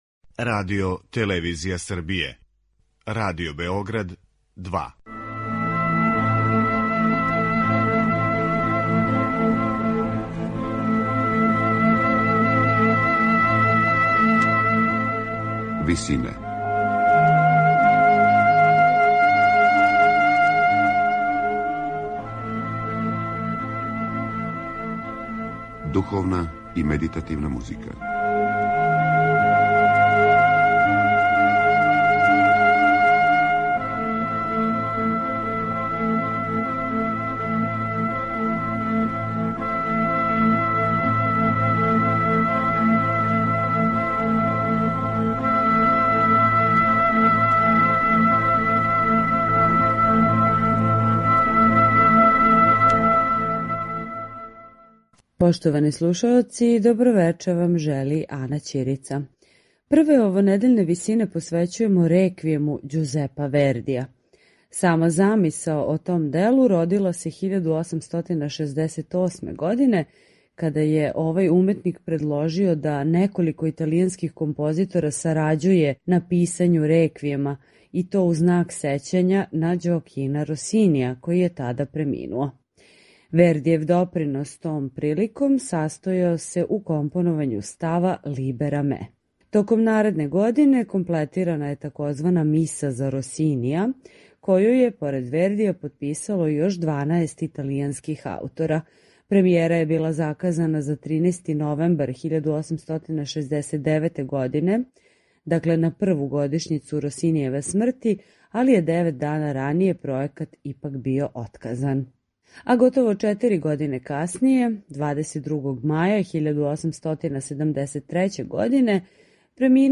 сопран
мецосопран
тенор